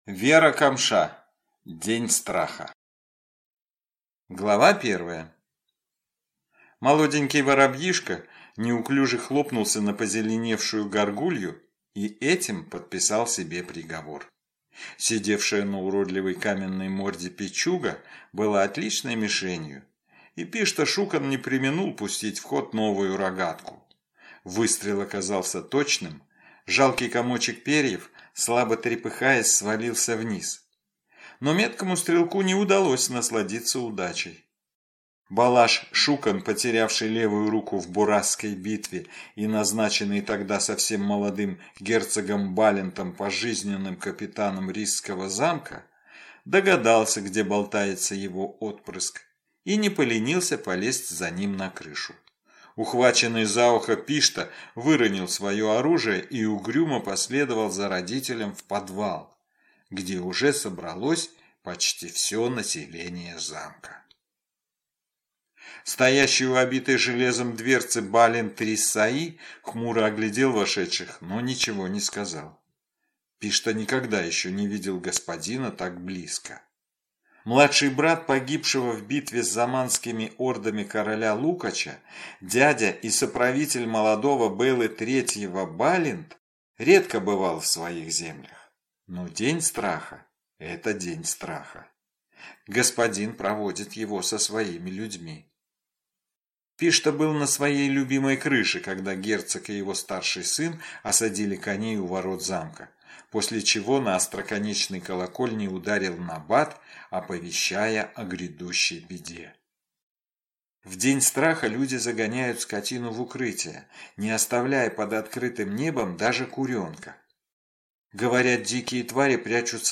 Аудиокнига День Страха | Библиотека аудиокниг